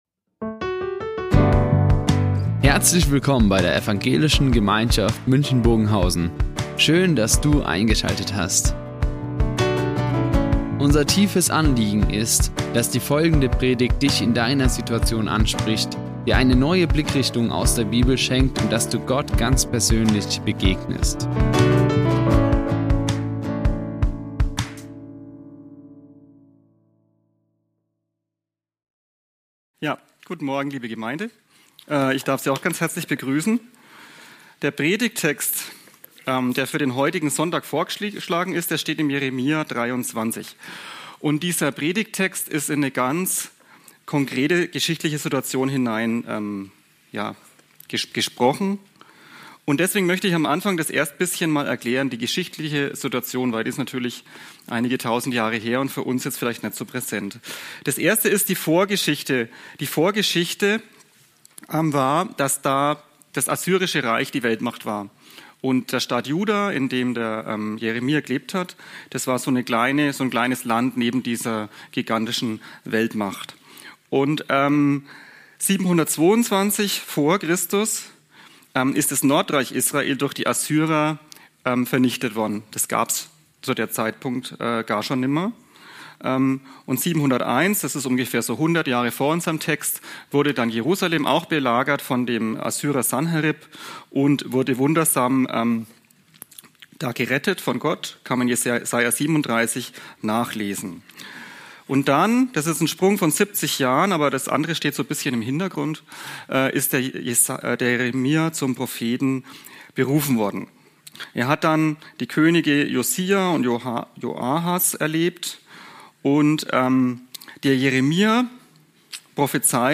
Das Hammer-Wort | Predigt Jeremia 23, 13-29 ~ Ev.
Die Aufzeichnung erfolgte im Rahmen eines Livestreams.